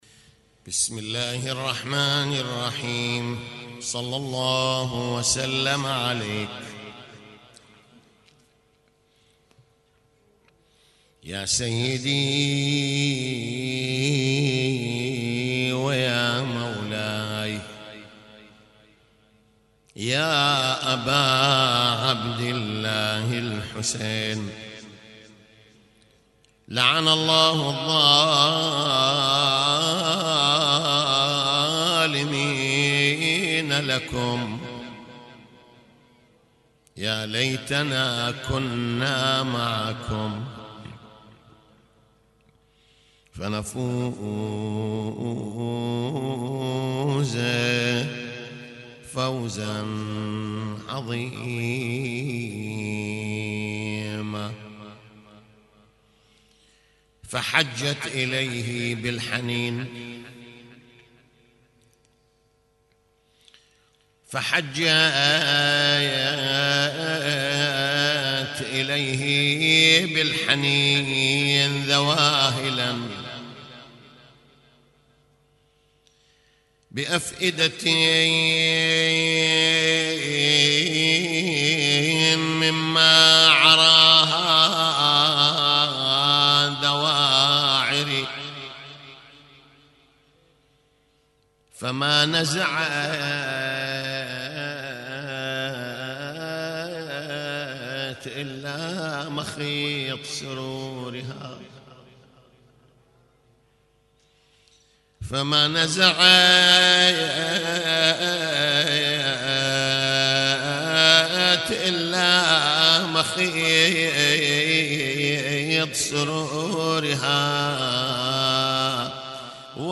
تغطية صوتية: يوم ثالث محرم 1438هـ في المأتم
يوم 3 محرم 1438هـ - مجلس العزاء